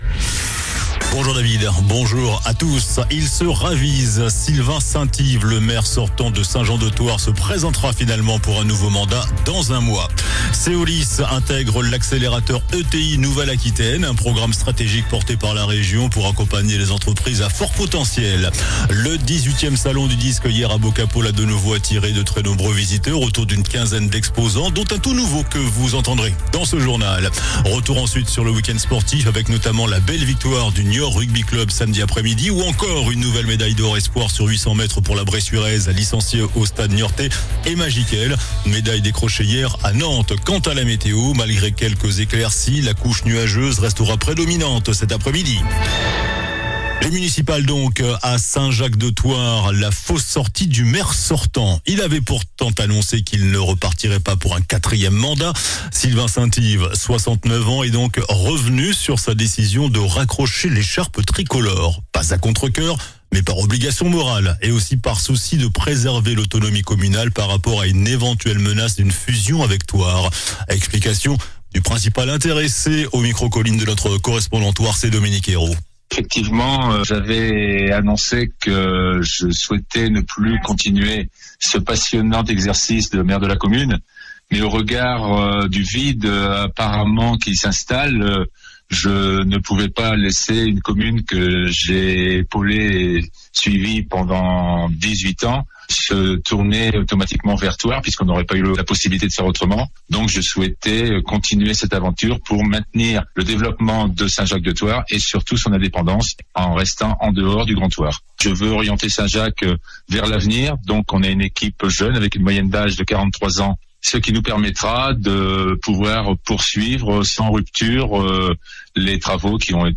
JOURNAL DU LUNDI 16 FEVRIER ( MIDI )